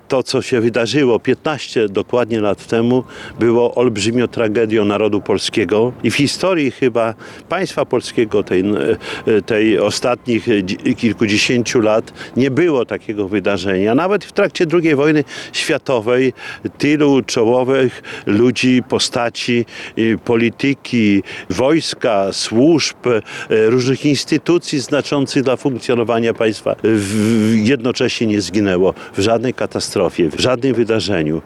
Dla uczczenia tej rocznicy w Suwałkach Czesław Renkiewicz, prezydent miasta wraz z przedstawicielami ratusza i Rady Miejskiej złożyli kwiaty pod pomnikiem na cmentarzu przy ul. Bakałarzewskiej.